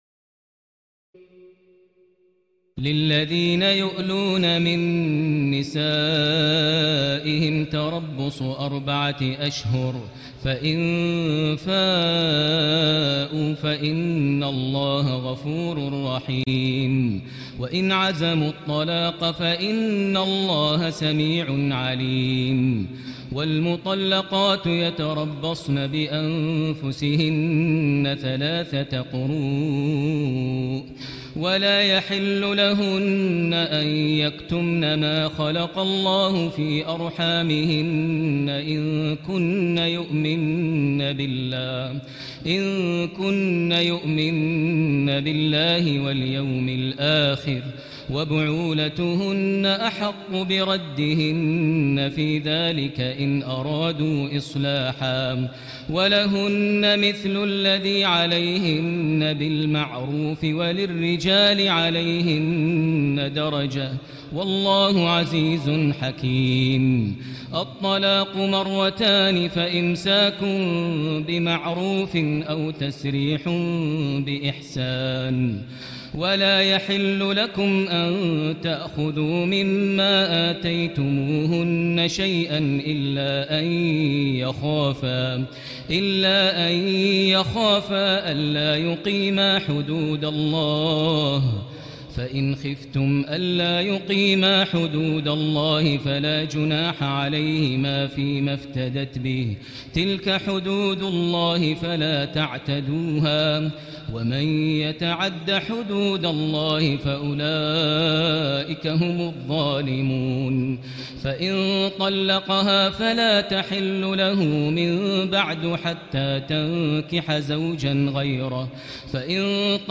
تراويح الليلة الثانية رمضان 1439هـ من سورة البقرة (226-271) Taraweeh 2 st night Ramadan 1439H from Surah Al-Baqara > تراويح الحرم المكي عام 1439 🕋 > التراويح - تلاوات الحرمين